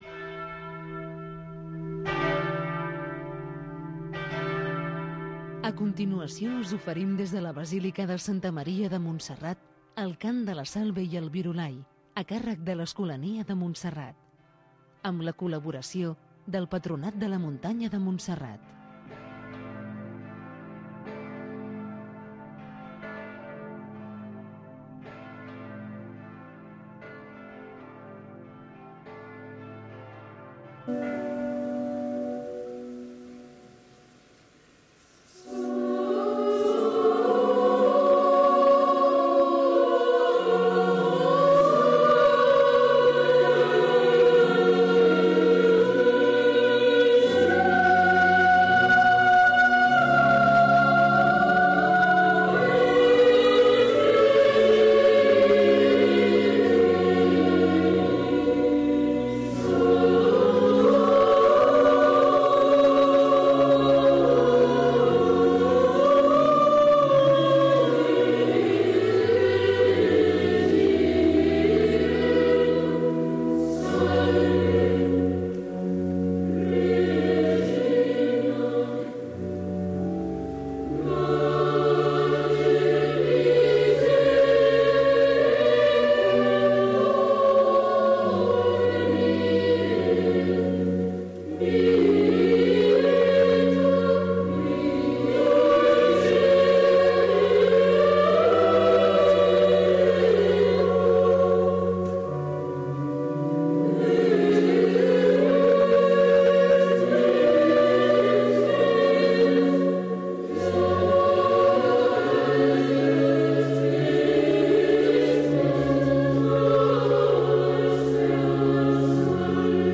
transmissió, des de la basílica de Santa Maria
per l'Escolania de Montserrat
Religió